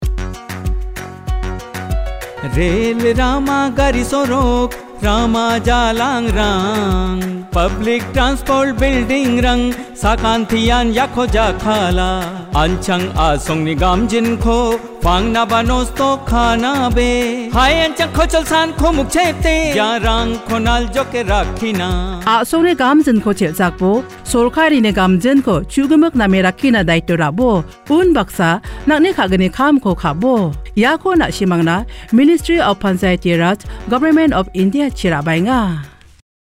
55 Fundamental Duty 9th Fundamental Duty Safeguard public property Radio Jingle Garo